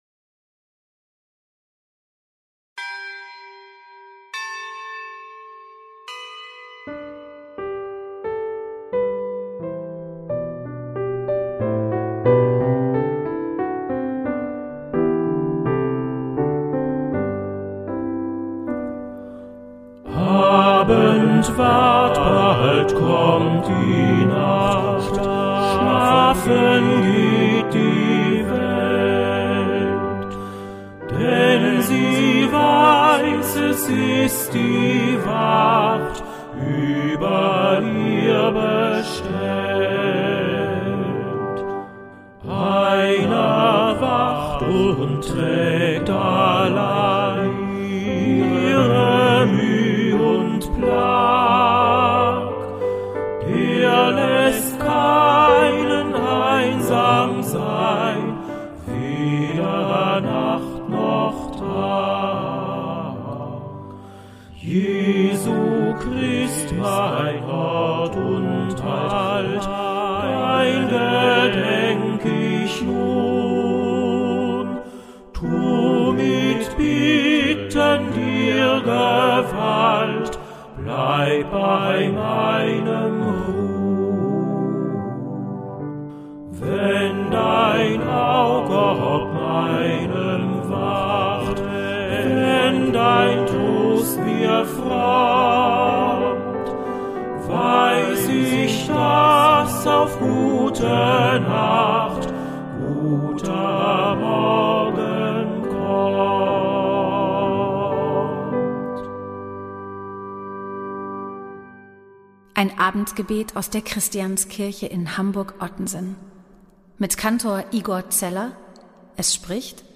Gebete, Texte und Gesänge aus der Christianskirche